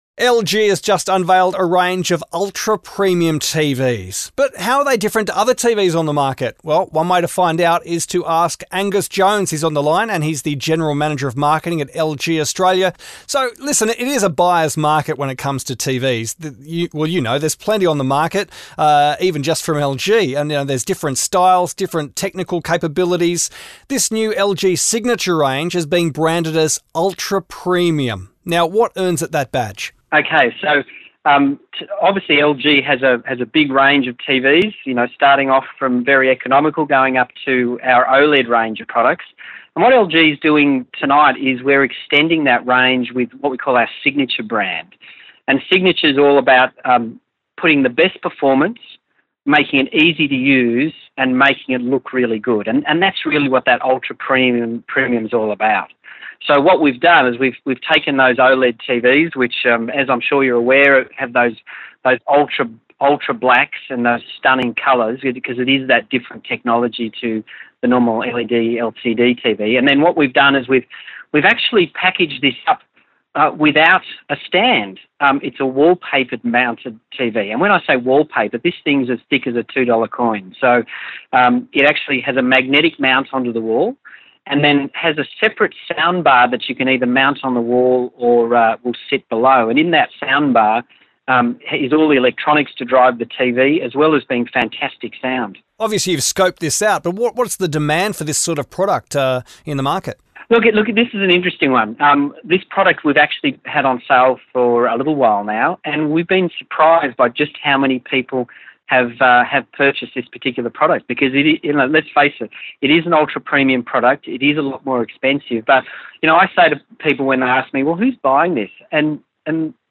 LG-Ultra-Premium-TV-Interview.mp3